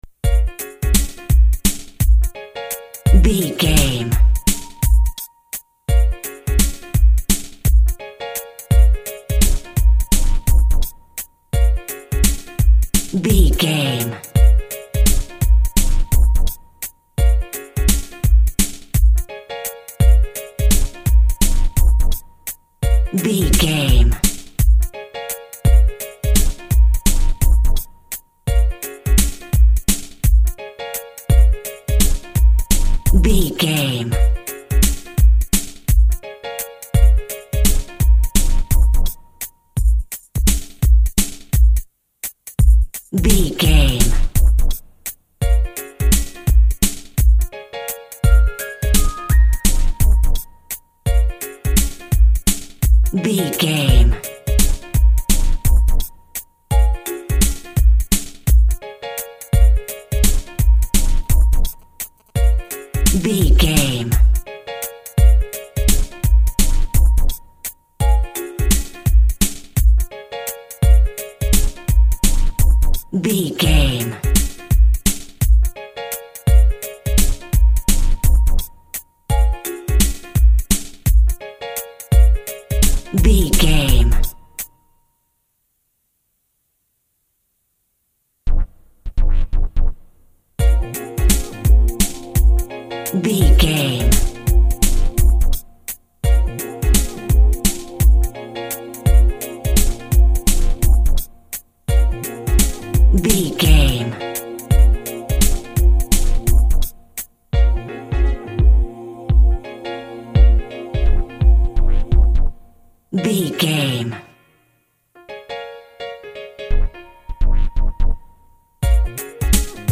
Aeolian/Minor
B♭
synth lead
synth bass
hip hop synths